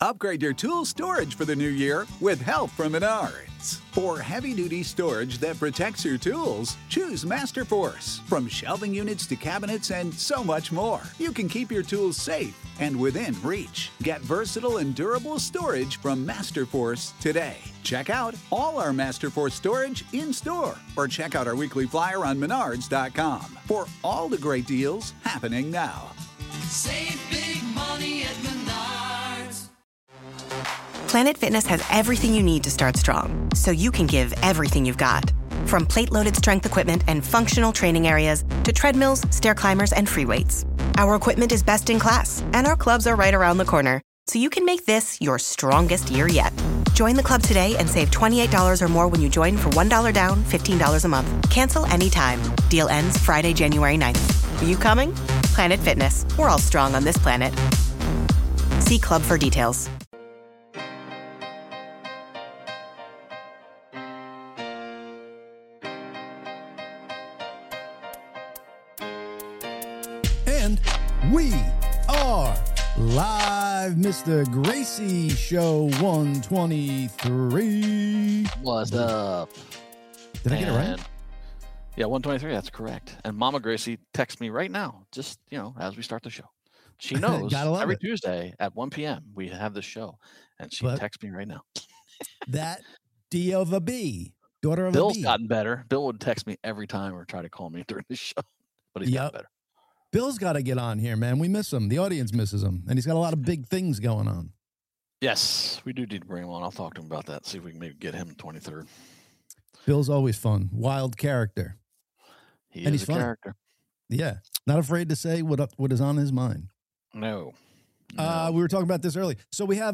The Gracious Two LIVE Podcast - Every Tuesday at 1 pm EASTERN...